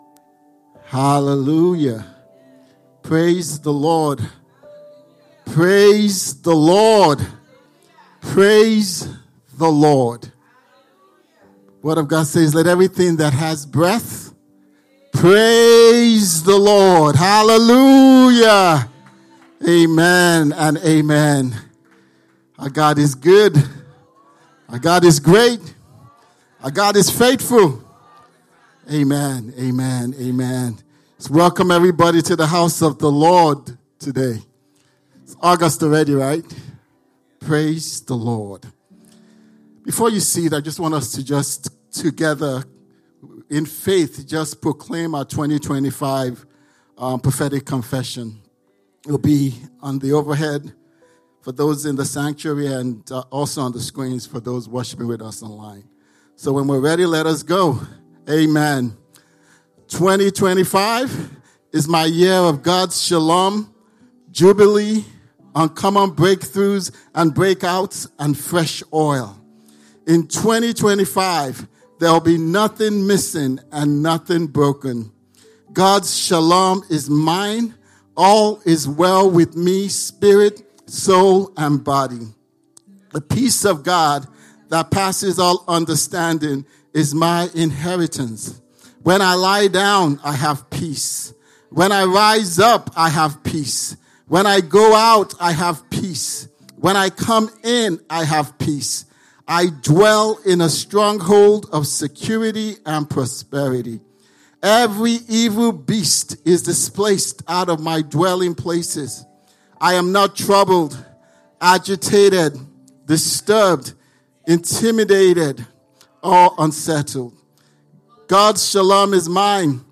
Sermons – Abundant Life International Church podcast